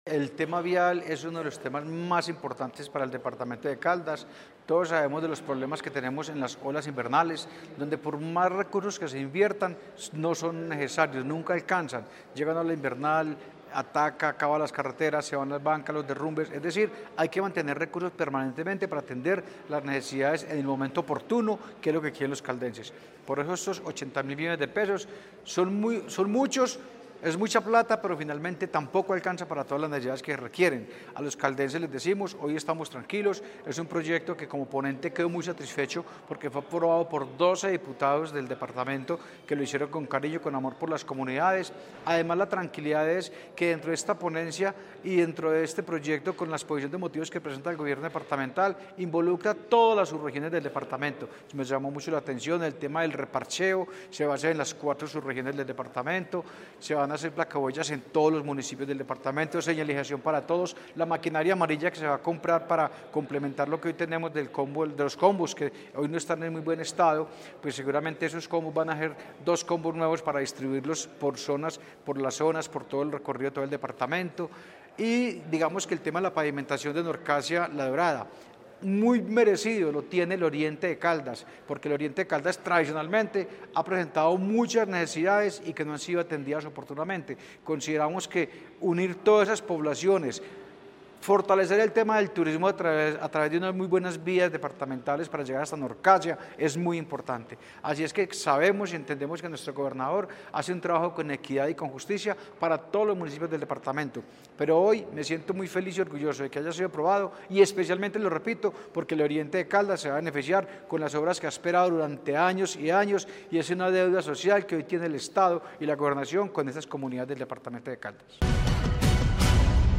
Jahír de Jesús Álvarez, diputado ponente del proyecto.
Jahir-de-Jesus-Alvarez-diputado-de-Caldas-1.mp3